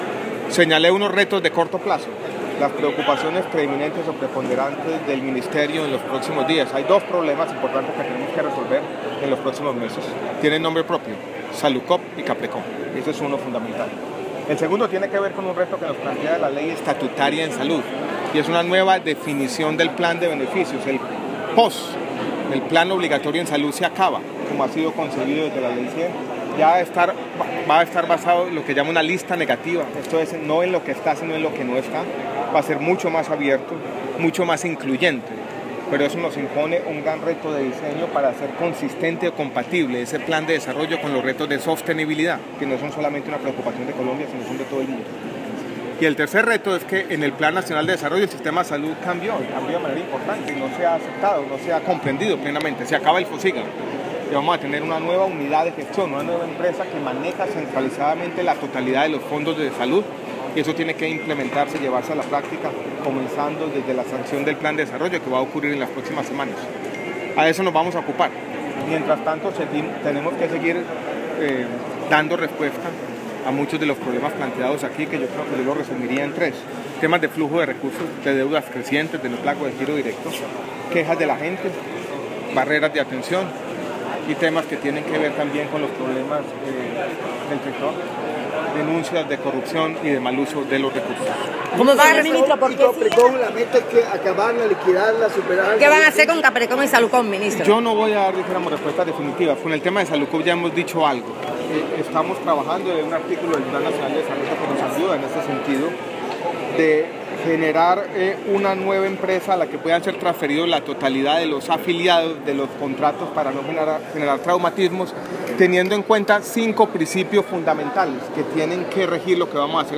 Audio, Declaraciones de MinSalud en Barraquilla sobre el Plan de Beneficios en Salud